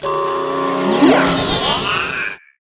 resteleport.ogg